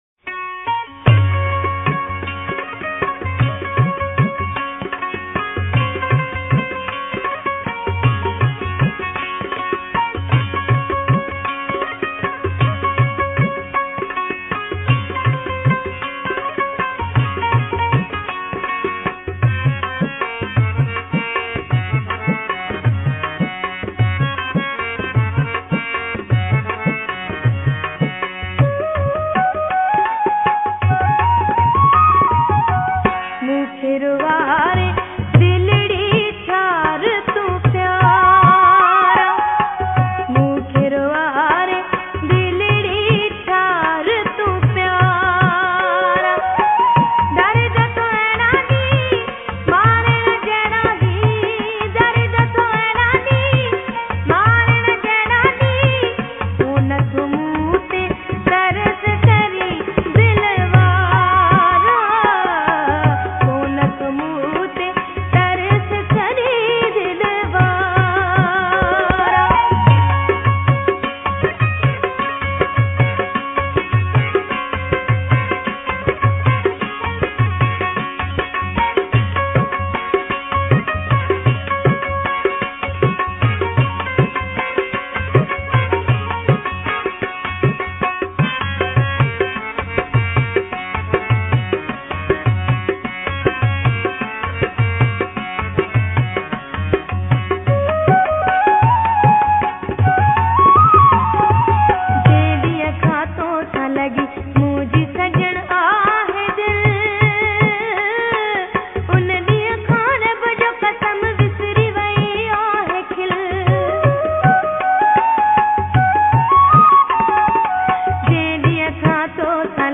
Kalaam/Poetry , ﭙﻨﺠﺎﺑﻰ punjabi
Sindhi kalam